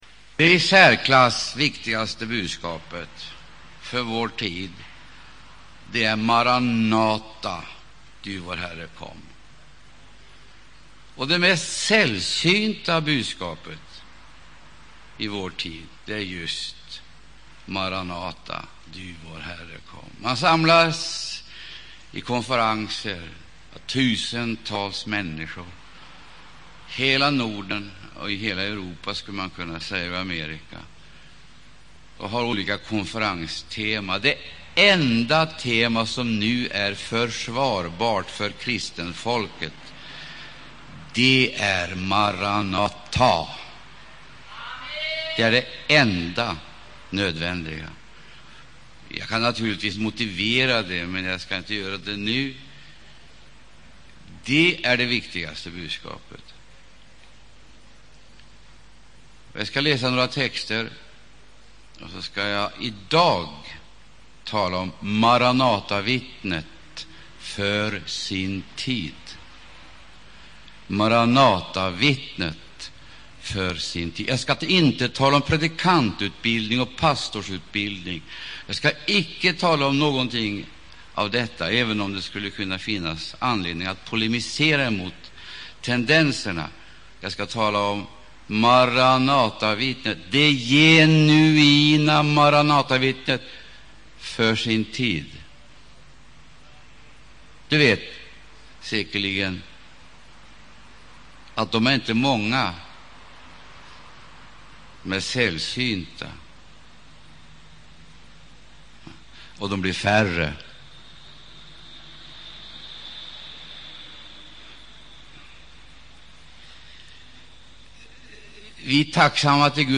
Första delen i en undervisningsserie
inspelad under Maranataförsamlingens sommarkonferens 1994.